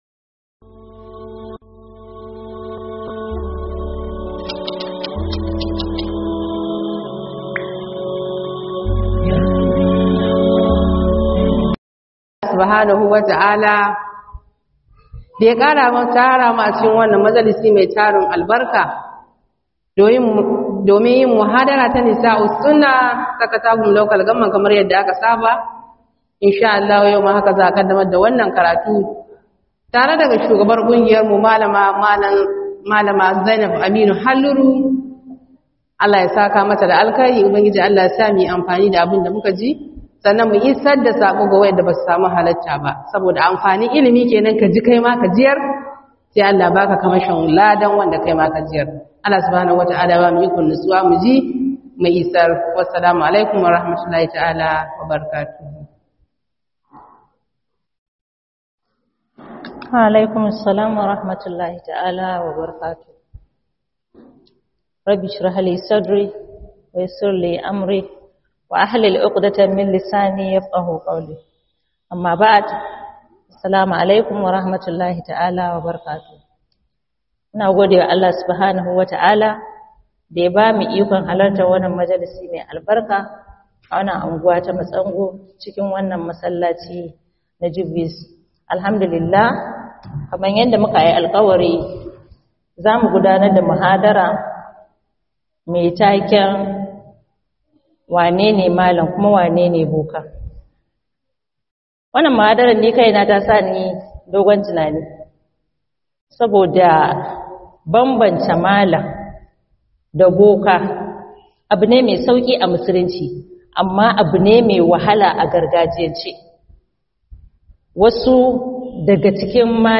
Bambamci Tsakanin Boka Da Malam - Muhadara